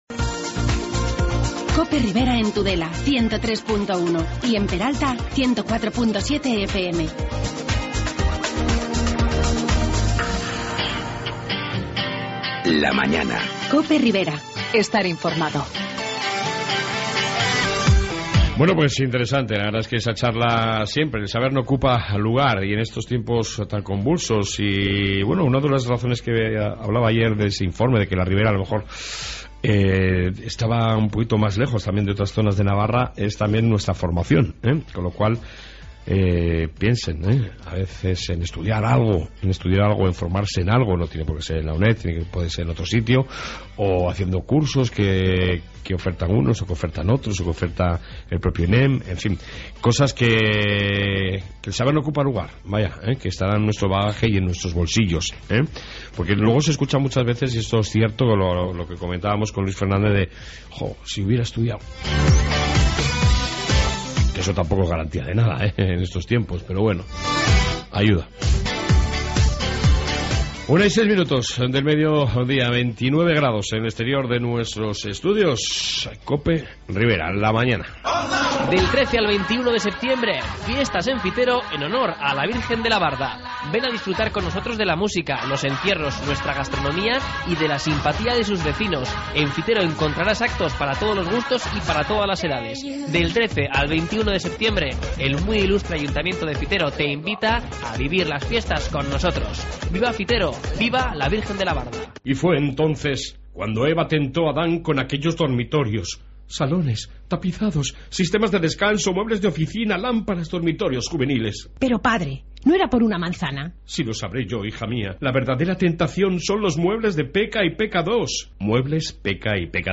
AUDIO: En esta 2 parte amplia Información Ribera y entrevista sobre el importante fin de semana cultural de Tudela